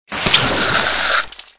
AA_squirt_neonwatergun_miss.ogg